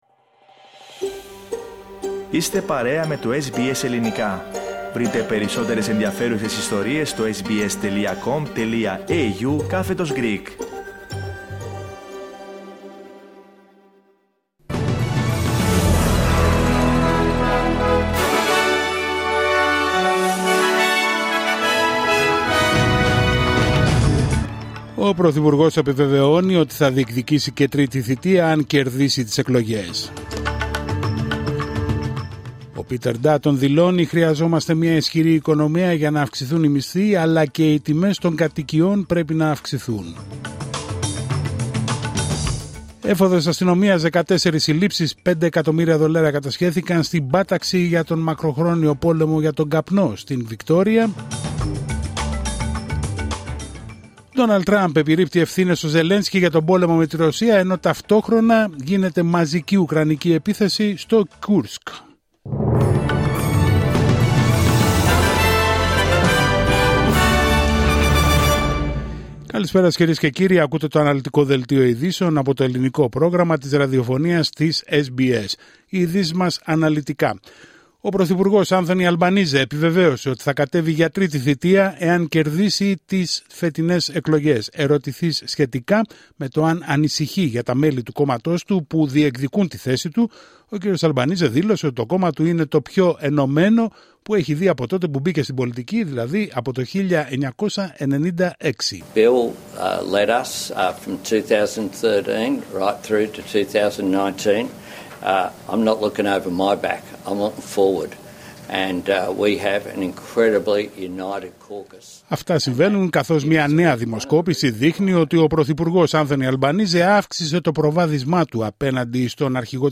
Δελτίο ειδήσεων Τρίτη 15 Απρίλιου 2025